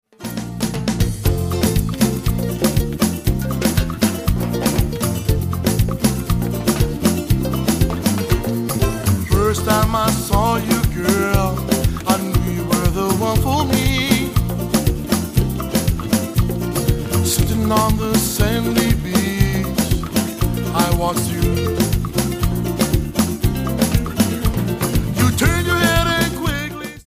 • Genre: Contemporary Hawaiian.
'ukulele
bass
guitar